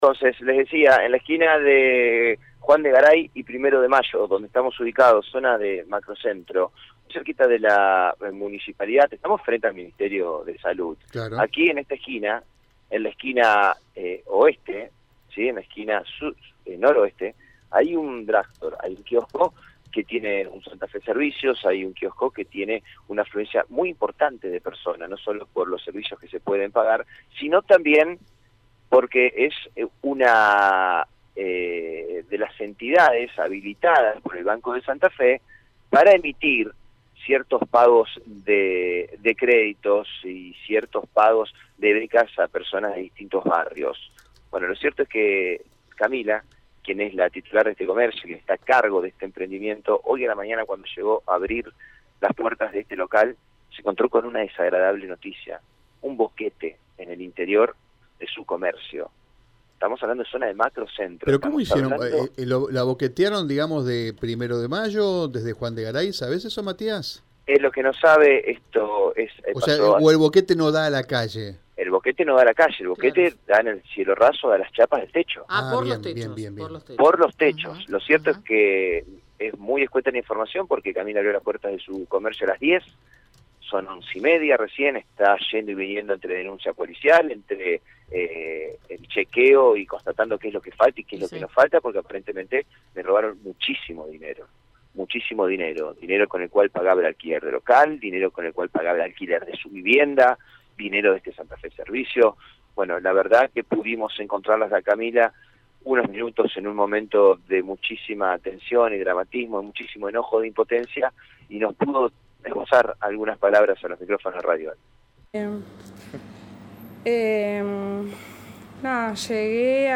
AUDIO DESTACADOAudiosSanta Fe